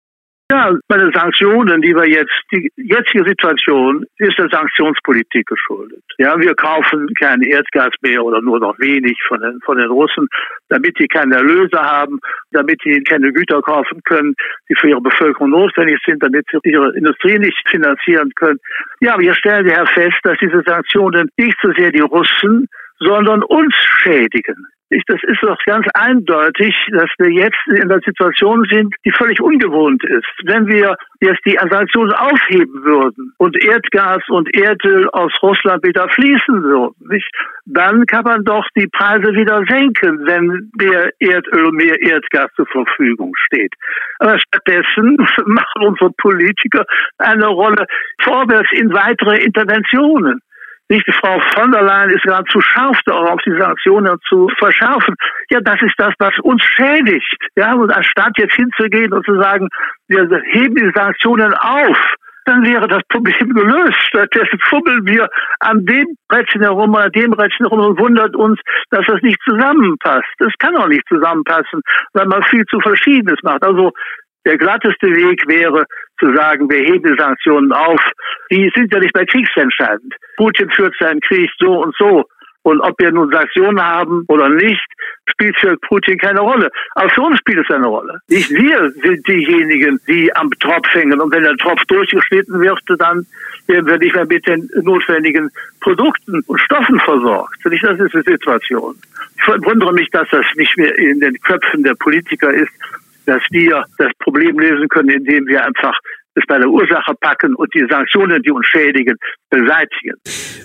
Interview mit Prof. em.